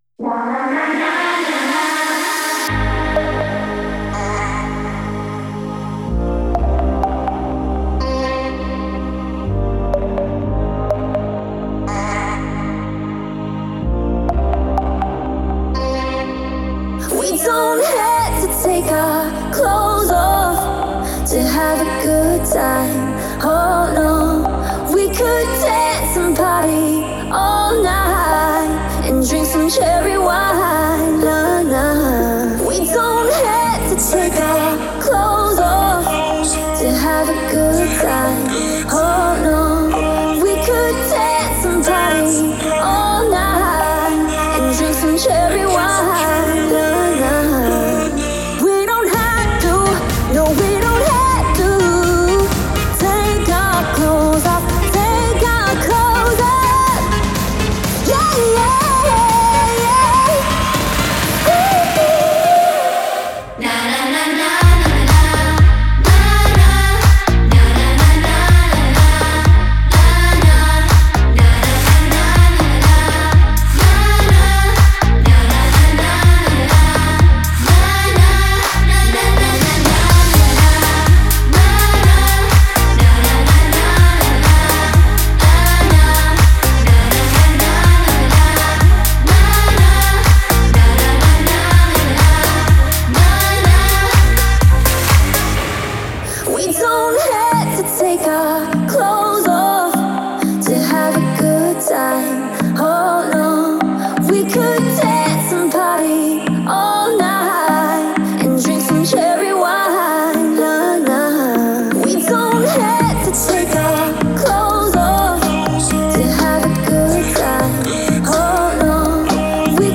энергичная танцевальная композиция в жанре EDM